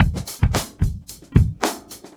Index of /90_sSampleCDs/USB Soundscan vol.46 - 70_s Breakbeats [AKAI] 1CD/Partition B/26-110LOOP A